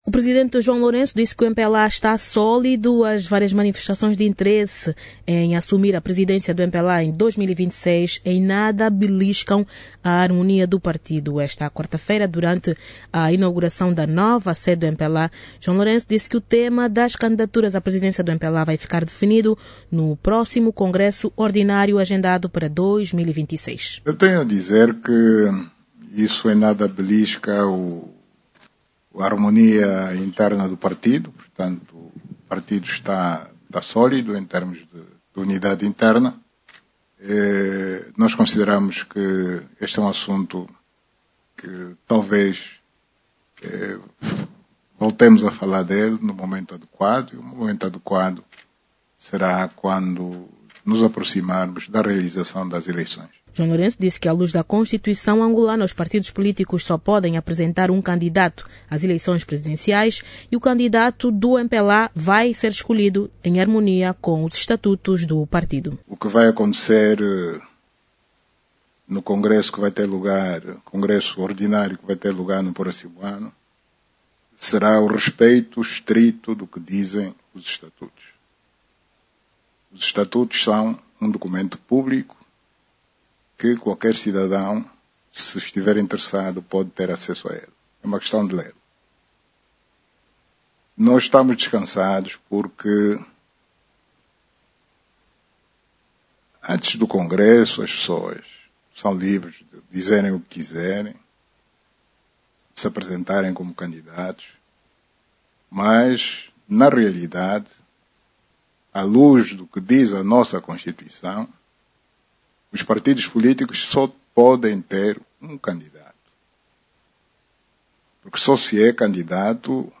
João Lourenço fez estas declarações momentos depois de inaugurar o novo edifício onde vai funcionar a Sede Nacional do MPLA.